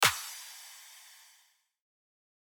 menuHeartbeat.mp3